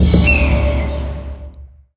Media:RA_Bo_Evo.wav UI音效 RA 在角色详情页面点击初级、经典和高手形态选项卡触发的音效